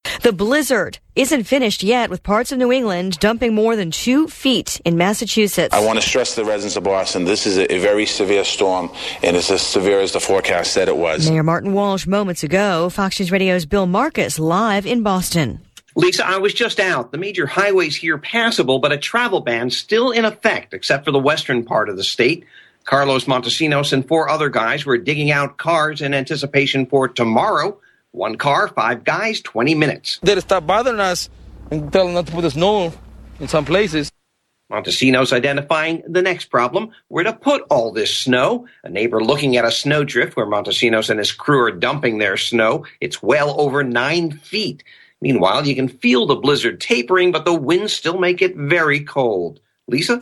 4PM LIVE